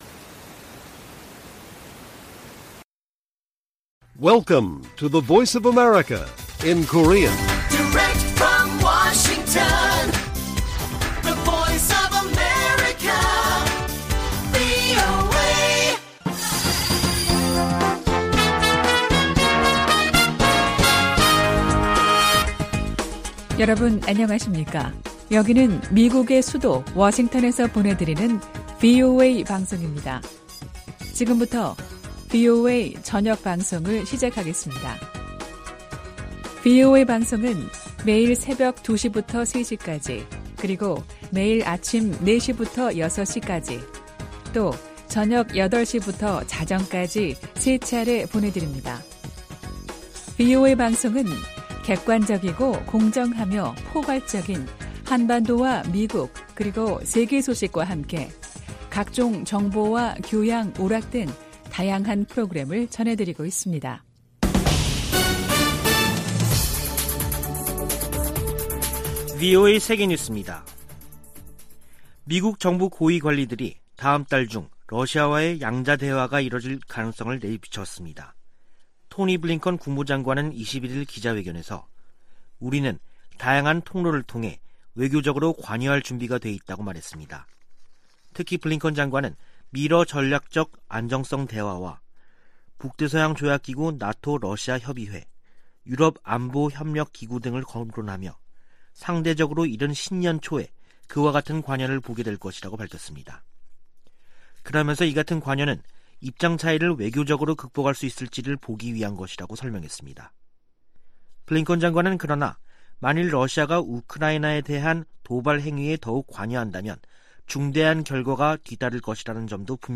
VOA 한국어 간판 뉴스 프로그램 '뉴스 투데이', 2021년 12월 22일 1부 방송입니다. 조 바이든 미국 대통령은 2022년 회계연도에도 북한과 쿠바 등에 인도적 목적 이외 비무역 관련 지원을 하지 않을 것이라고 밝혔습니다. 토니 블링컨 미 국무장관은 인도태평양 지역에 정책과 자원을 집중하고 있다고 밝혔습니다. 미국과 한국은 한국의 증대된 역량과 자율성에 대한 열망을 감안해 동맹을 조정하고 있다고 미 의회조사국이 분석했습니다.